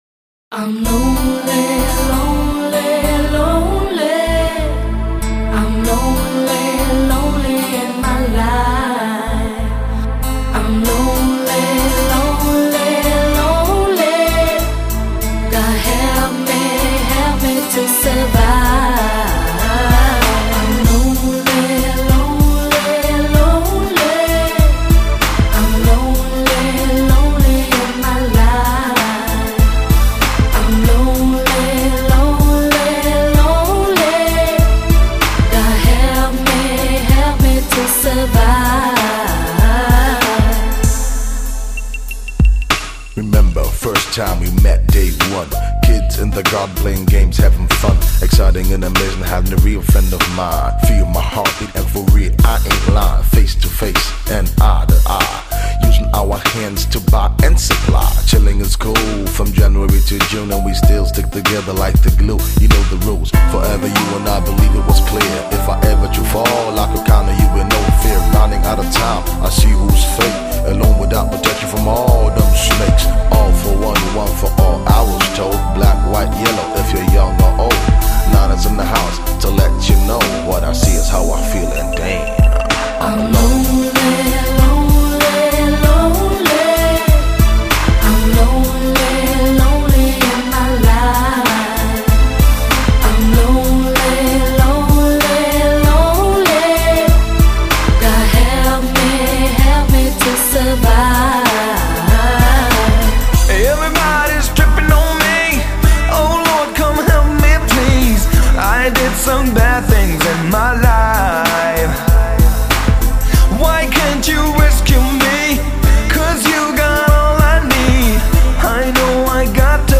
风格：慢摇
动情处的伤心呐喊，颓废时的无奈低吟，节奏布鲁斯，跳舞音乐，HIP HOP结合的